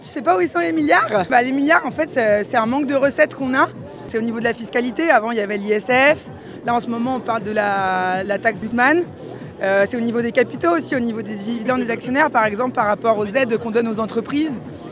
Étudiants, fonctionnaires, retraités, ouvriers : Ils étaient environ un millier à s’être réunis hier pour la manifestation à Mende dans le cadre du mouvement national de grève du 18 septembre.